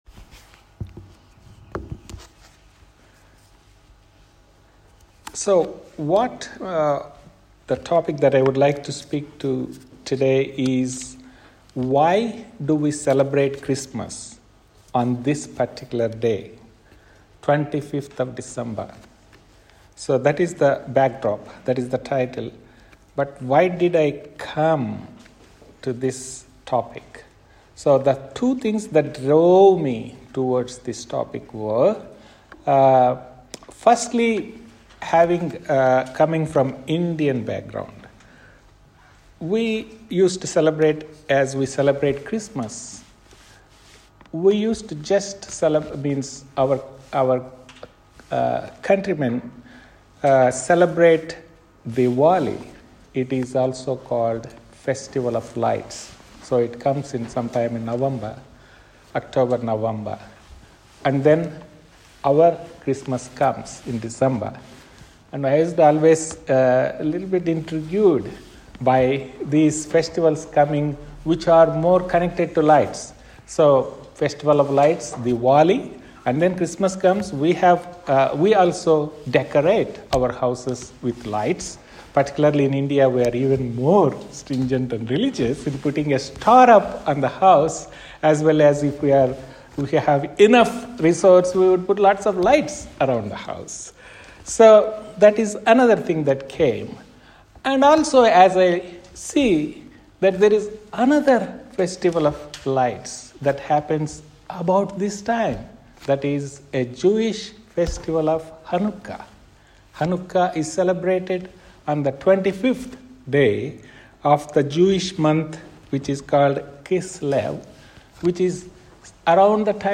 Christmas-message-2021.mp3